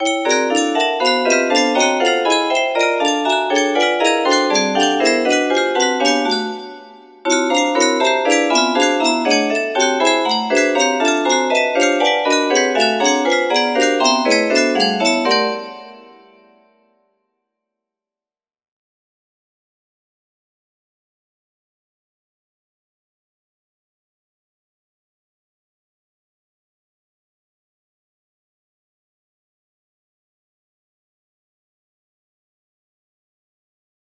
P8 music box melody